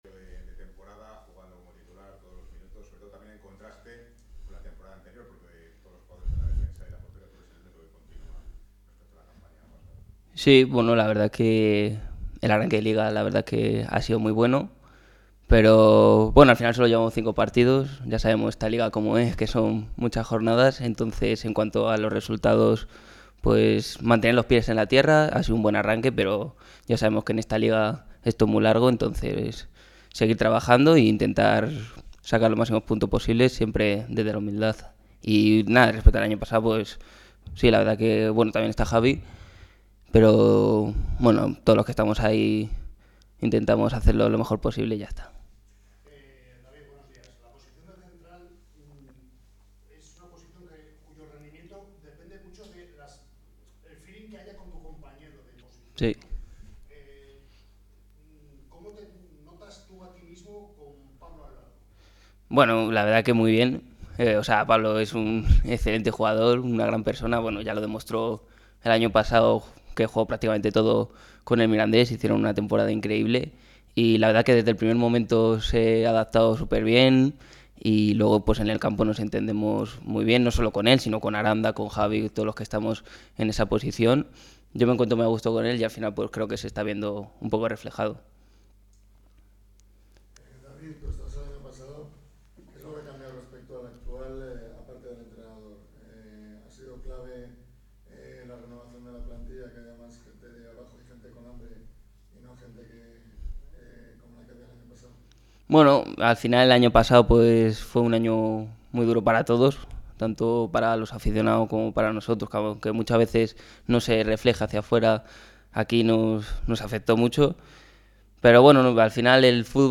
Intentaremos mantener el nivel toda la temporada”, señaló el canterano este miércoles en sala de prensa.